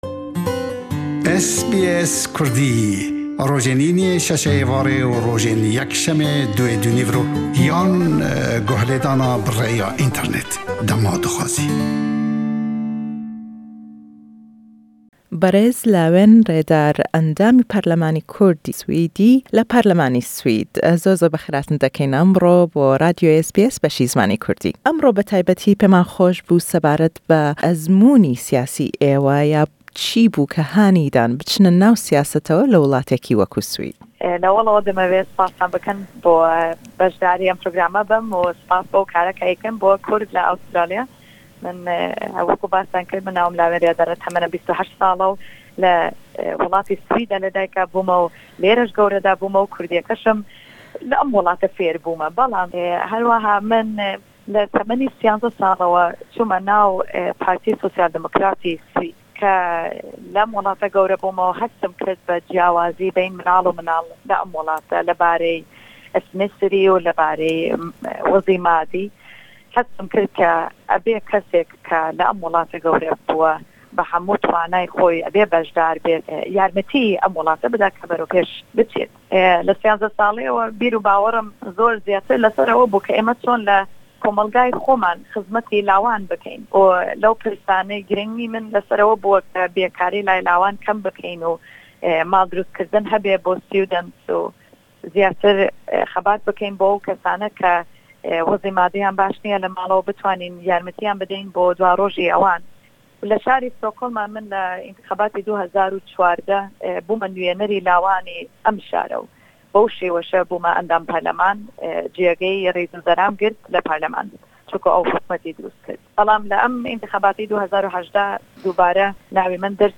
Le em hevpeyvîne le gell xatû Rêdar, pirsiyarî lê dekeyn sebaret be serkewtinyan le biwarî destkewtekanîyan le naw siyasetî Swîdî da û ew babetaney bo ew gringin ke karîyan le ser bikat we andamêkî parleman û amojgarî ew bo lawanî Kurd le henderan.